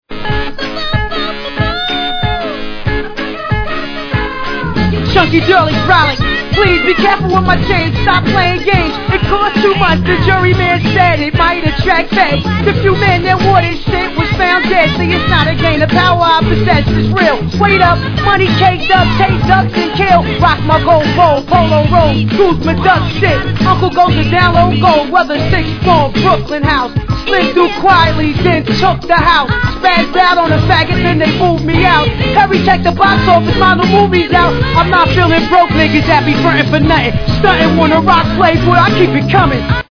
Tag       EASTCOAST 　 HIP HOP